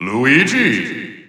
The announcer saying Luigi's name in English and Japanese releases of Super Smash Bros. 4 and Super Smash Bros. Ultimate.
Luigi_English_Announcer_SSB4-SSBU.wav